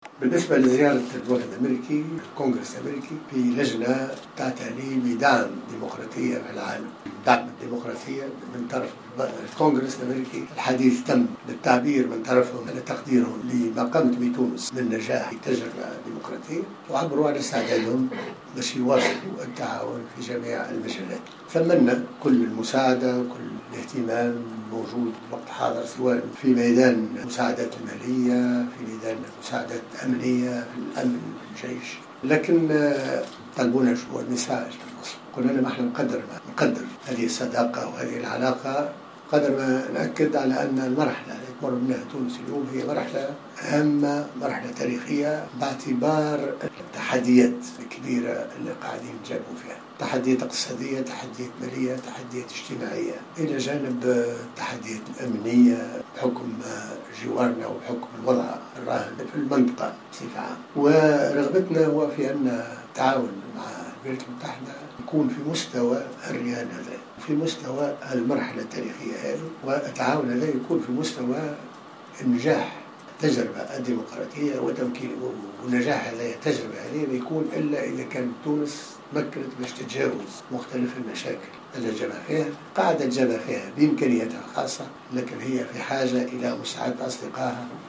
وصرح الناصر، خلال ندوة صحفية عقدها اليوم السبت بمجلس نواب الشعب بباردو، عقب لقائه بوفد من الكونغرس الأمريكي يتقدمه النائب الجمهوري فارن بوشنان، بان الوفد البرلماني الامريكي اعرب عن تقديره لما حققته تونس من نجاحات مكنتها من الوصول الى مرحلة الاستقرار السياسي وبناء ديمقراطية ناشئة، مؤكدين العزم على مواصلة دعم التعاون مع تونس خاصة في المجال الأمني، نظرا لعلاقة الاستقرار الوثيقة بدفع التنمية والاستثمار والنهوض بالسياحة.